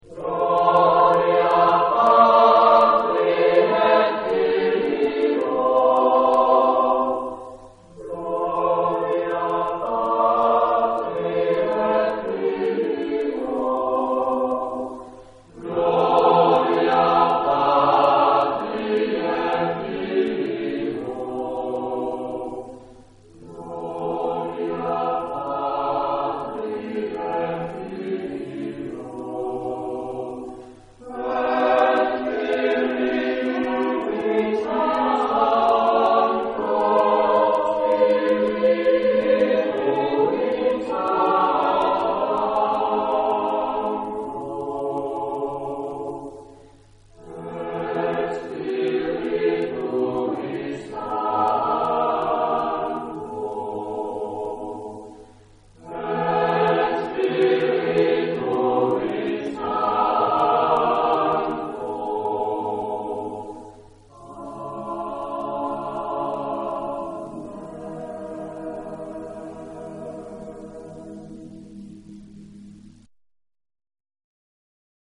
Género/Estilo/Forma: Renacimiento ; Sagrado ; Motete
Tipo de formación coral: SATB  (4 voces Coro mixto )
Tonalidad : do mayor